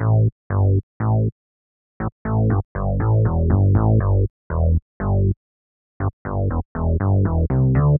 29 Bass PT4.wav